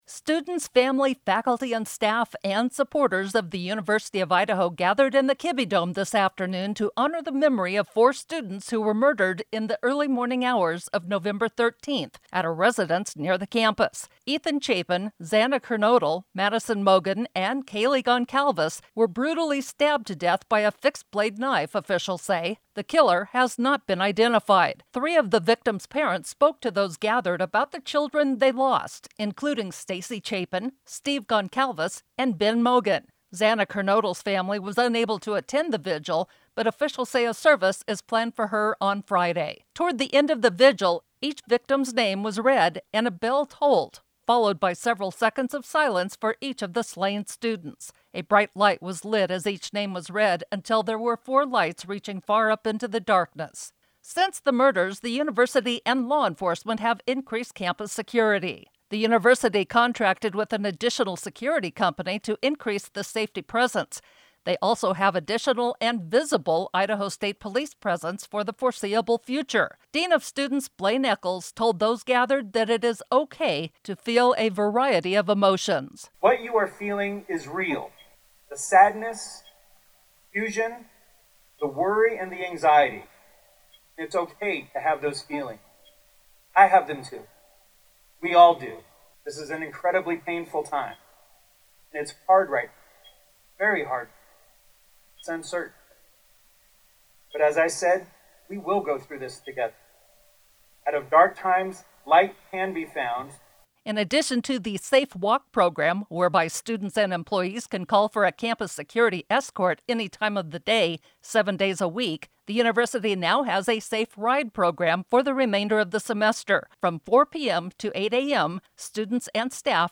Toward the end of the vigil, each victim’s name was read and a bell tolled, followed by several seconds of silence for each of the slain students.